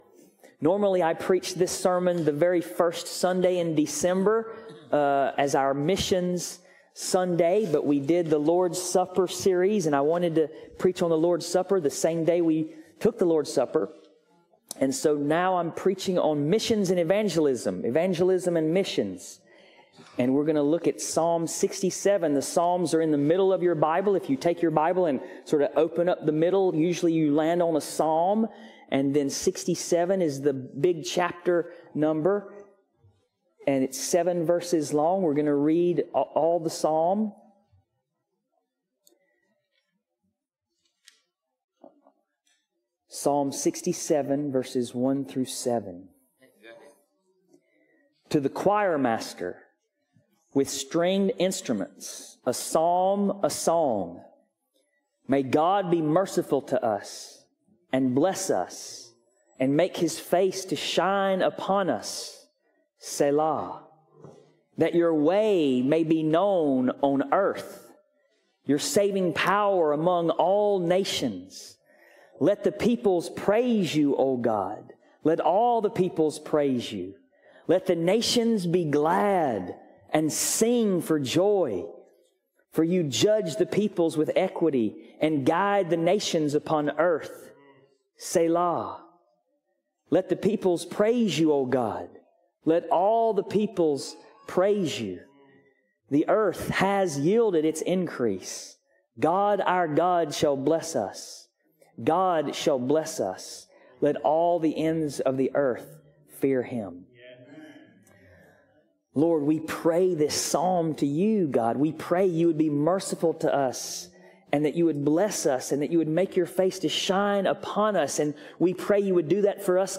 Passage: Psalm 67 Service Type: Sunday Morning Christ Jesus Is God’s Blessed Grace Look!